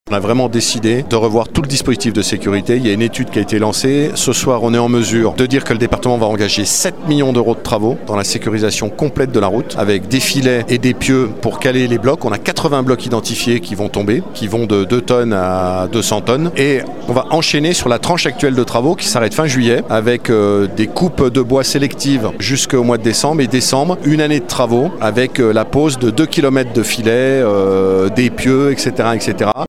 Son président Martial Saddier nous en parle.